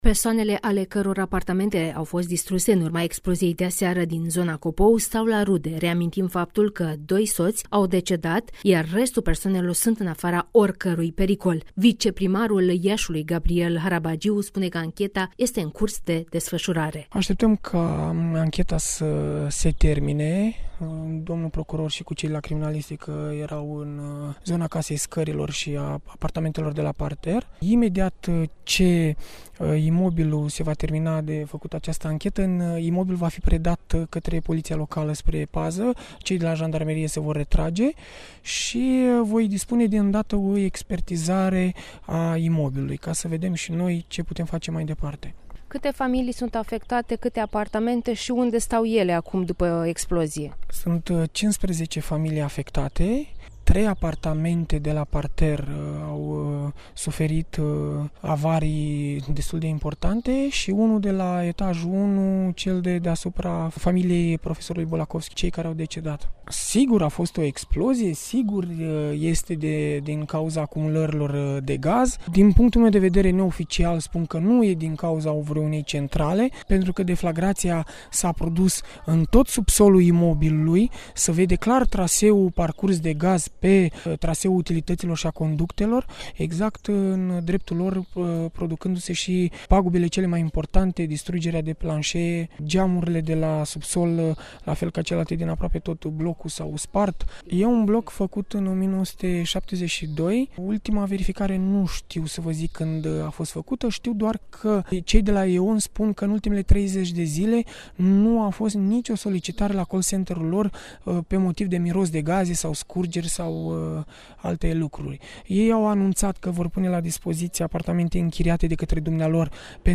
(AUDIO/REPORTAJ) Anchete în cazul exploziei de aseară din cartierul Copou